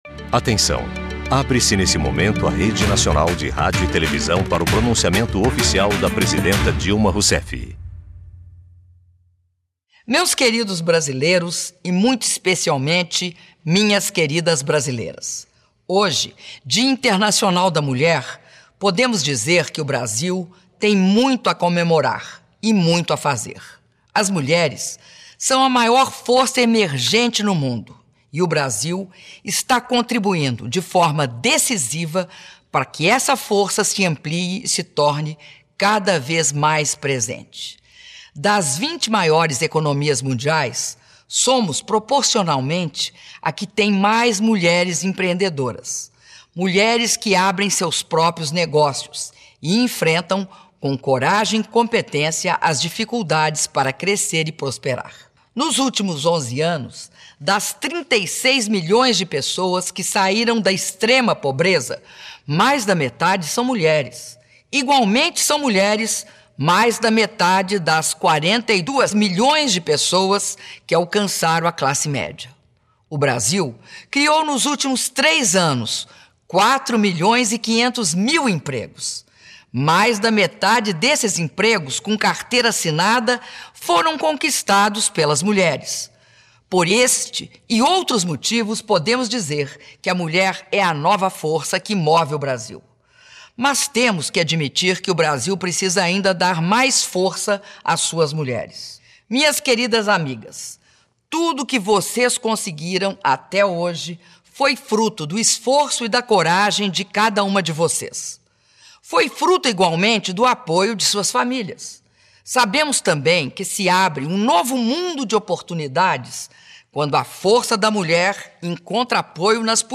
Áudio do pronunciamento da Presidenta da República, Dilma Rousseff, por ocasião do Dia Internacional da Mulher (08min22s)